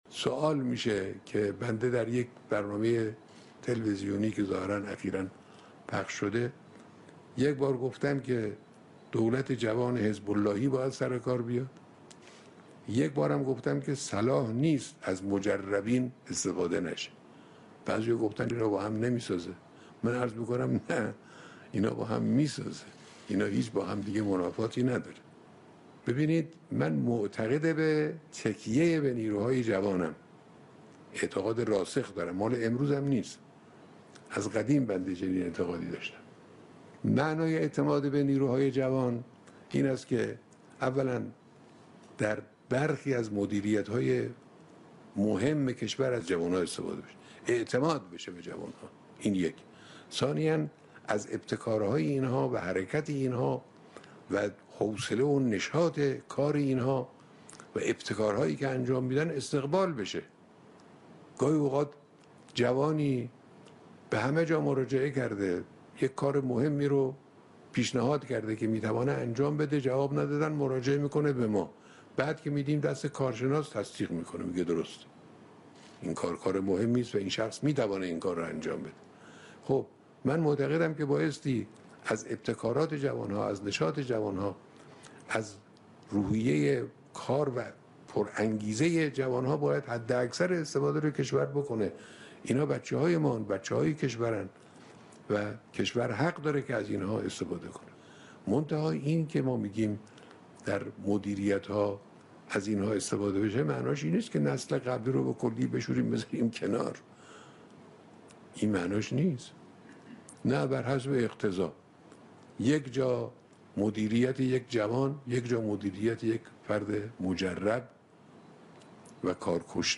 حضرت آیت‌الله خامنه‌ای صبح امروز (جمعه) به مناسبت چهل و سومین سالگرد قیام 19 دی مردم قم علیه رژیم ستمشاهی در سال 1356، در یك سخنرانی زنده و تلویزیونی با مردم سخن گفتند.